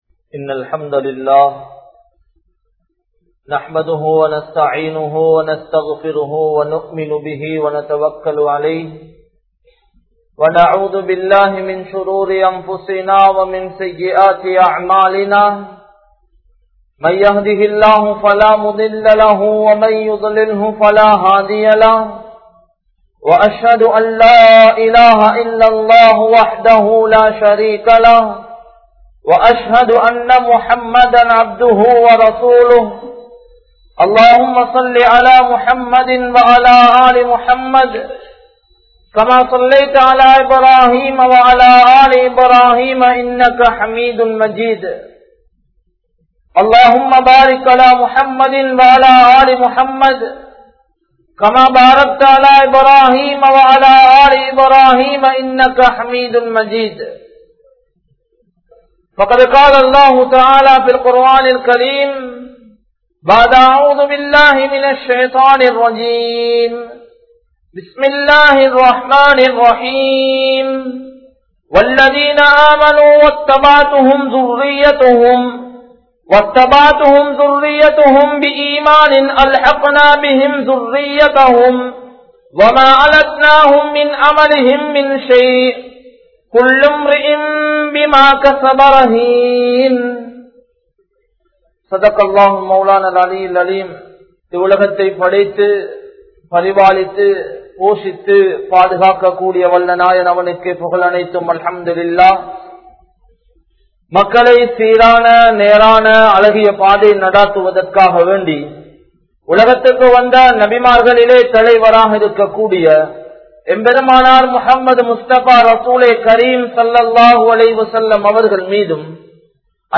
Thirumanathin Pin Kanavan Manaivi(திருமணத்தின் பின் கணவன் மனைவி) | Audio Bayans | All Ceylon Muslim Youth Community | Addalaichenai